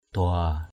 /d̪ʊa:/ 1.
dua.mp3